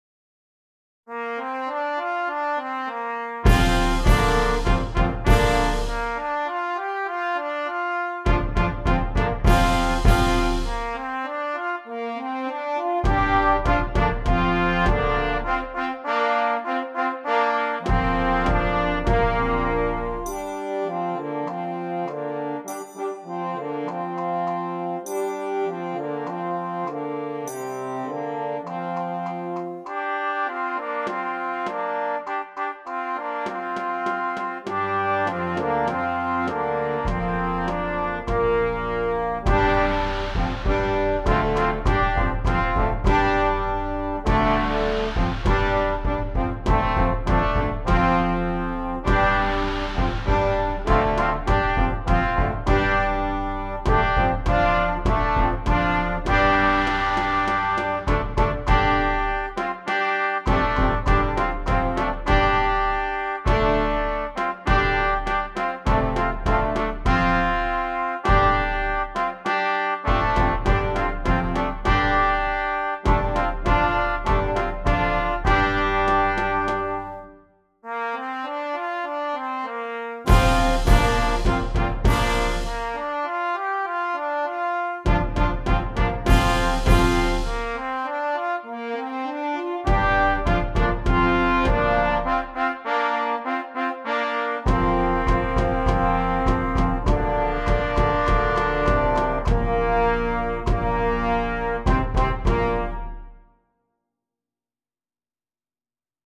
Gattung: Für Blechbläserensemble
Besetzung: Ensemblemusik Blechbläserensemble
Dies verleiht dem Stück eine echte asiatische Klangfülle.